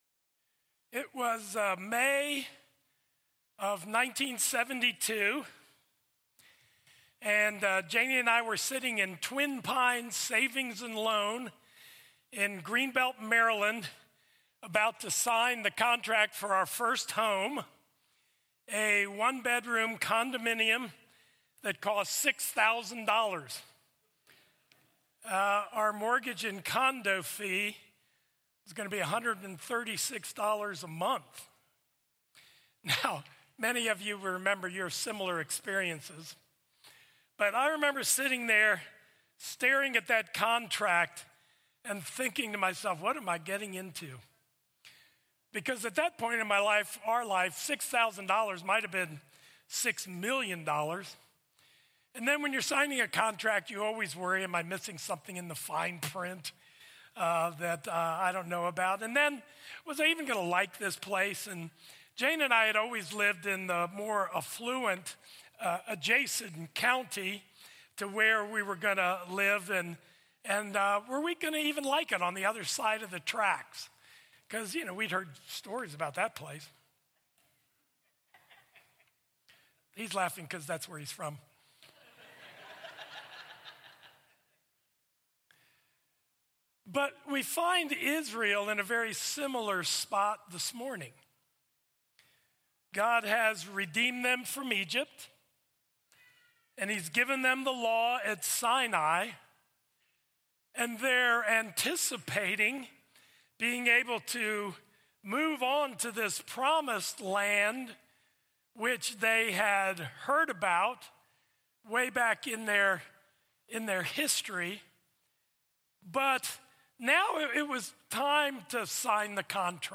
Crossway Community Church